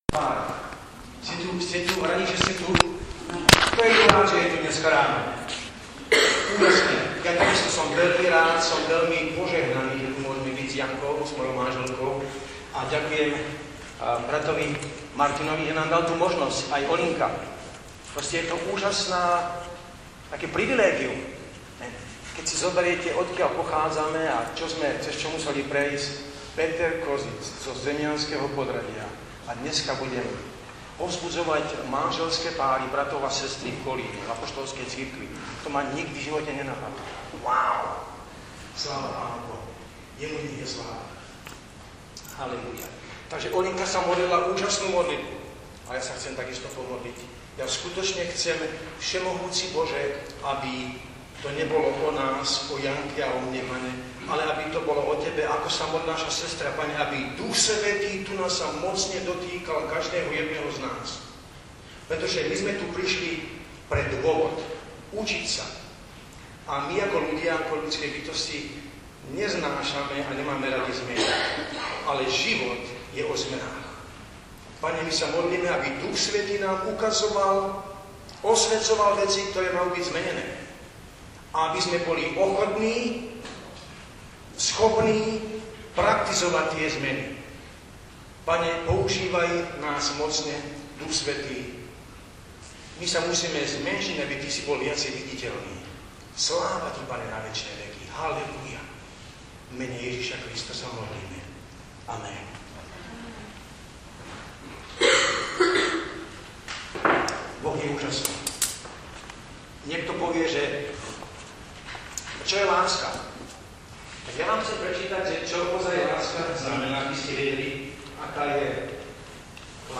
Konference pro (nejen) manželské páry 2016 "Zítra znovu a jinak"
seminář 1 - 1. část